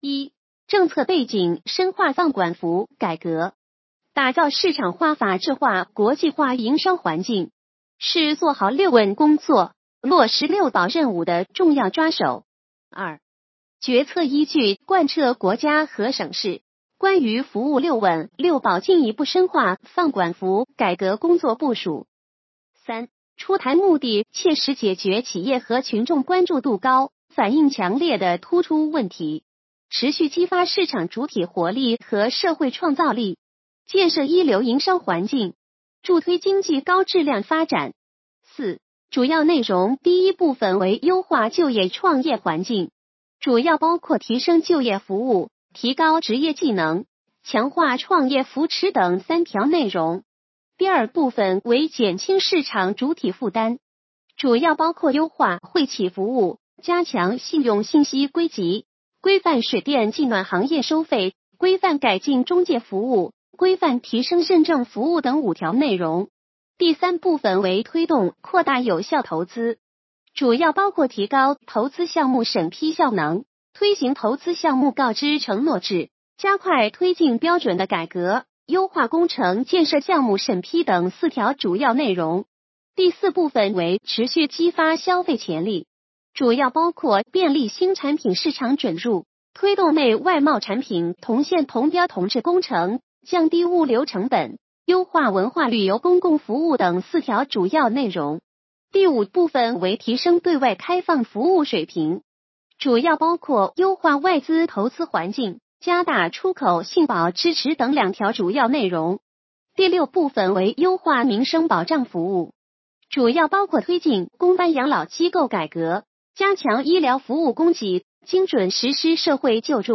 语音解读：山亭区人民政府办公室关于服务“六稳”“六保”进一步深化“放管服”改革的实施意见